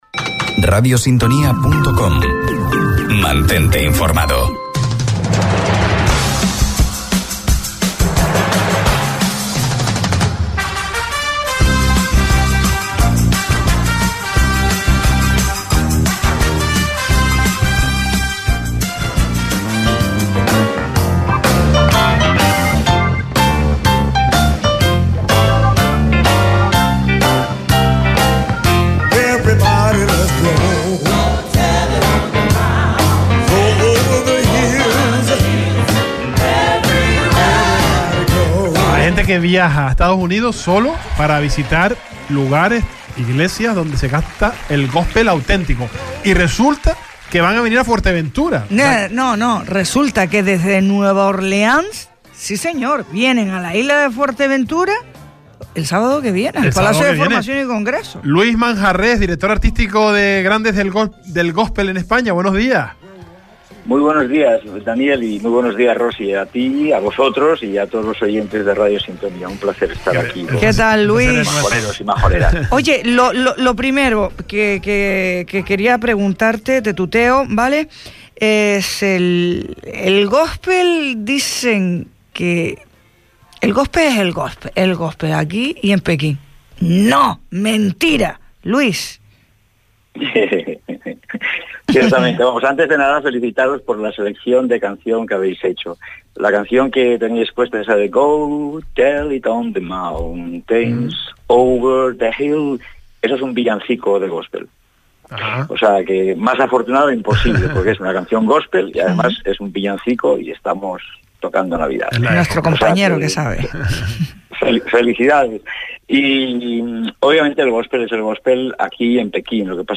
El Salpicón | Entrevista